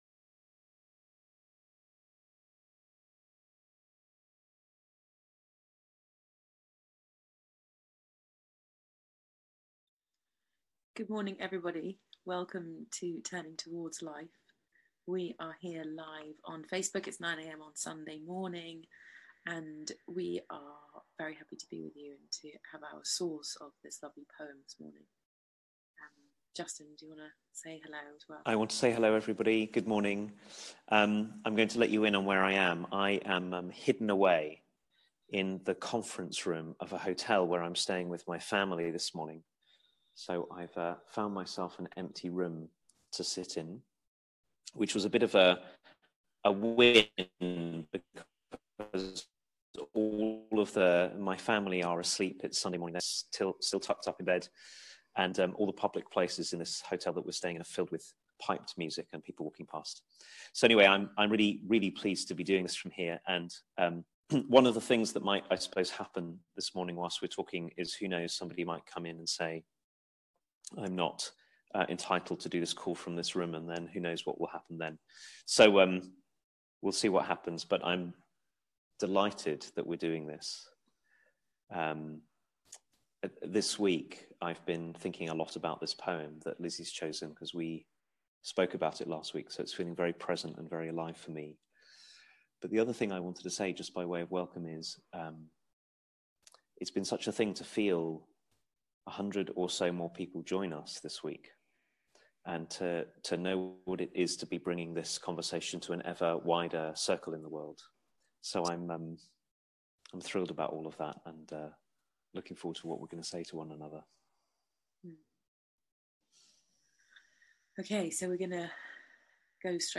A conversation
a weekly live 30 minute conversation